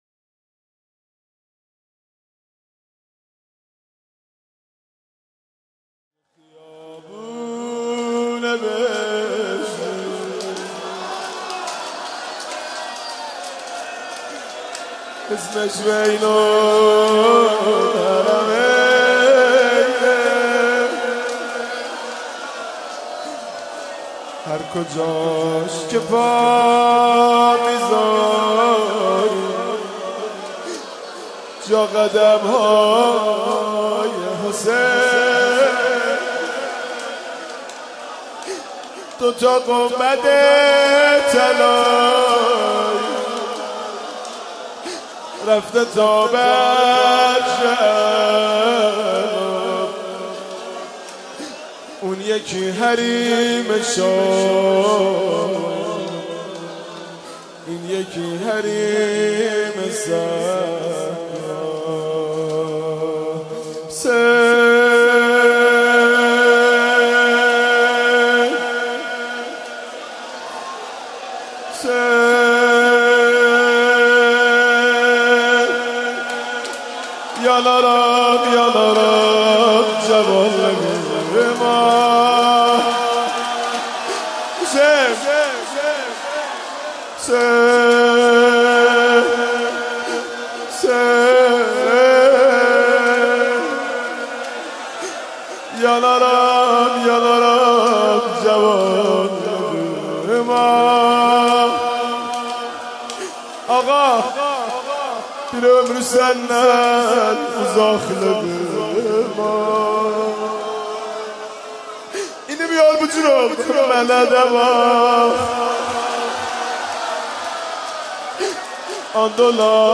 ویژه ایام سوگواری ماه محرم